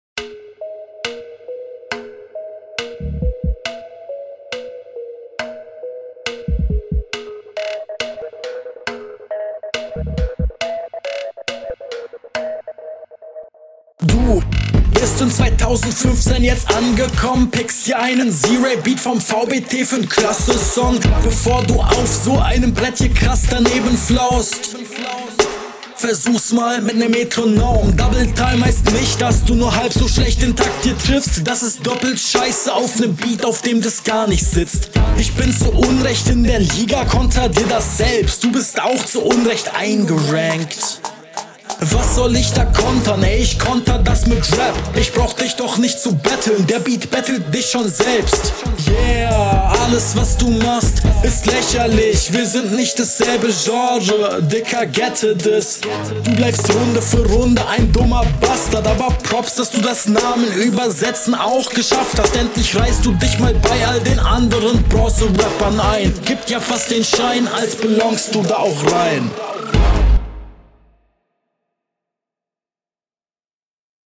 Niederklassiges Battle